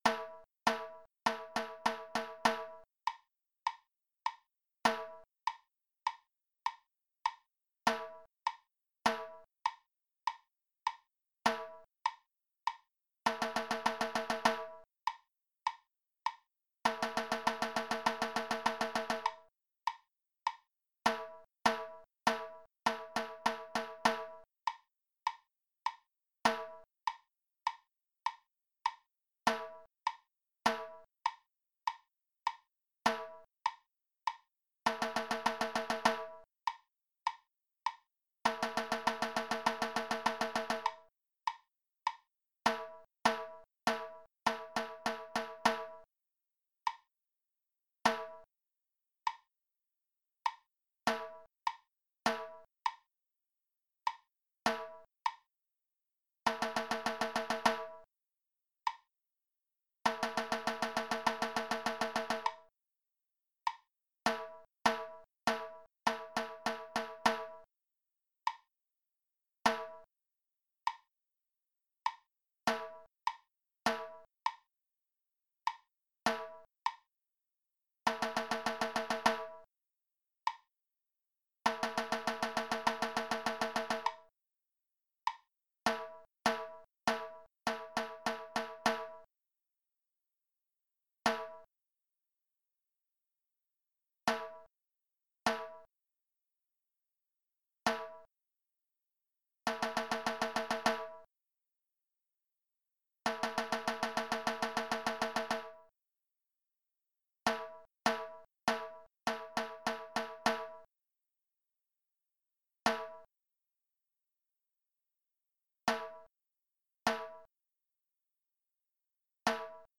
At the beginning of the example, you will hear the metronome play steadily. Eventually the metronome will drop out and the drum will continue to play.